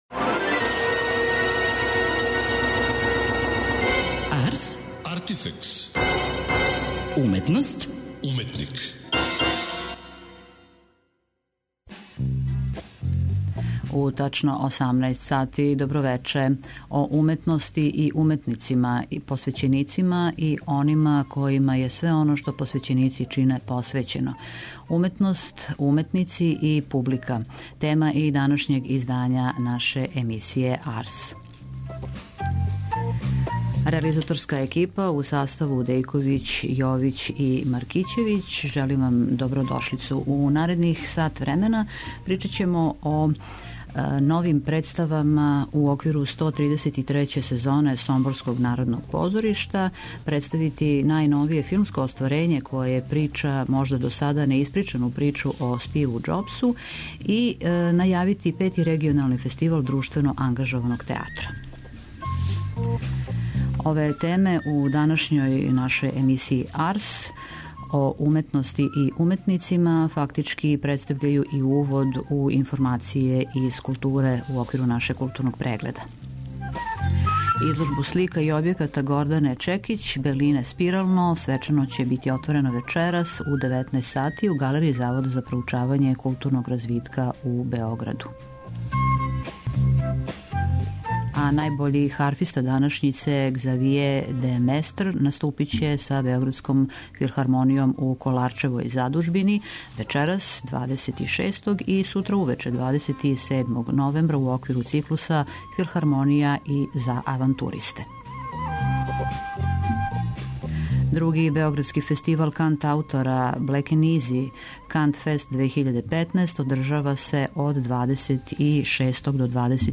Представљамо вам нови филм који данас стиже у наше биоскопе, "Стив Џобс" , у коме насловну улогу тумачи Мајкл Фасбендер , а режију потписује Оскаровац Дени Бојл , а уживо ћемо се јавити са отварања Петог регионалног фестивала друштвено ангажованог театра Off frame .